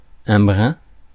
Ääntäminen
IPA: [bʁɛ̃]